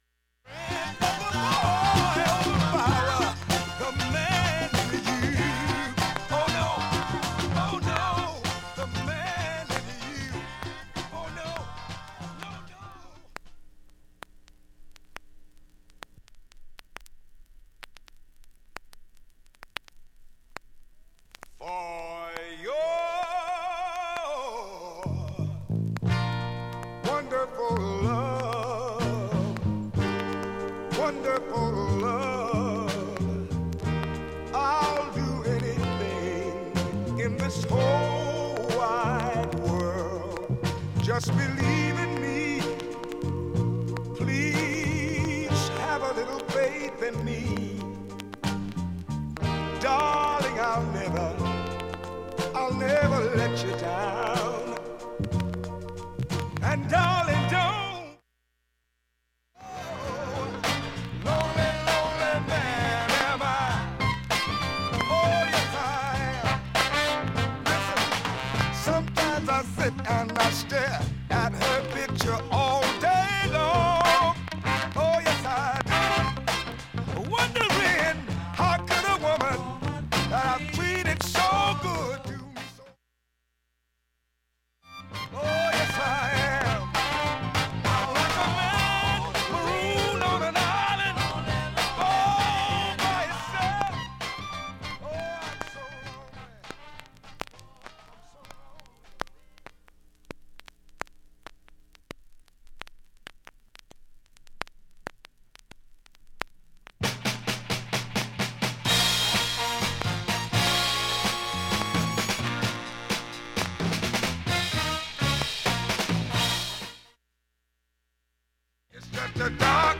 バックチリプツ出る箇所も
かなり少なく普通に聴けます。
周回プツ出ますがかすか。試聴１分
現物の試聴（上記録音時間(4m44s）できます。音質目安にどうぞ